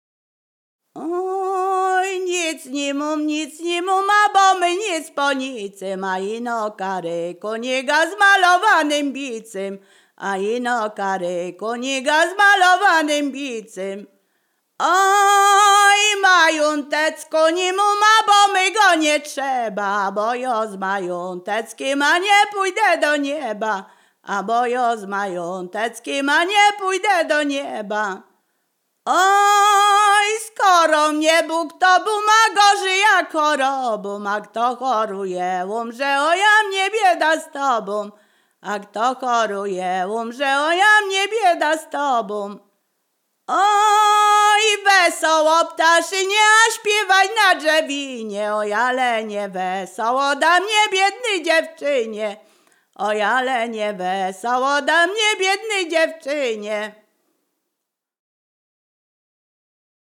Ziemia Radomska
Przyśpiewki
przyśpiewki miłosne obyczajowe